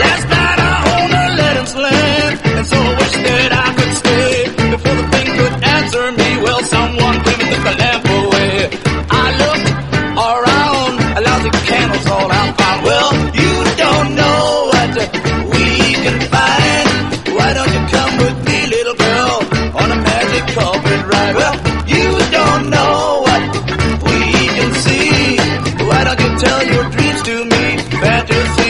ハードなサイケ/ファンキー・ロック69年作！